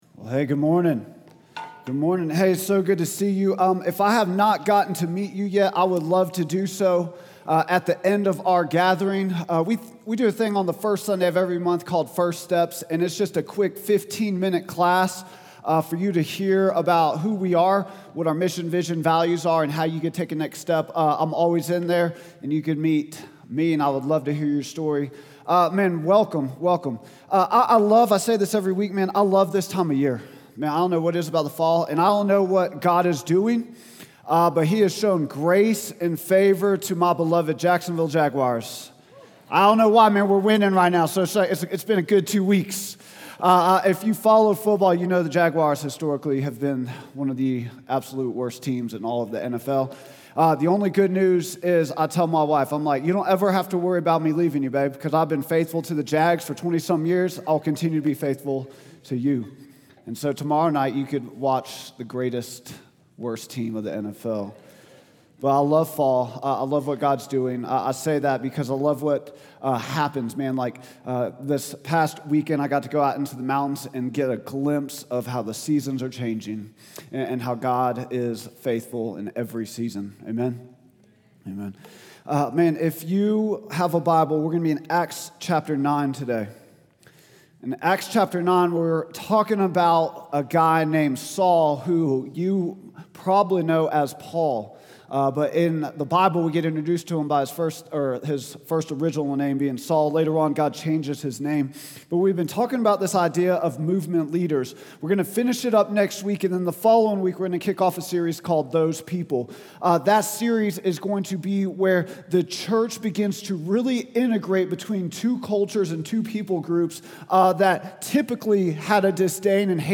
Vision & Values Meet Our Team Statement of Faith Sermons Contact Us Give Movement Leaders | Acts 9:1-31 October 5, 2025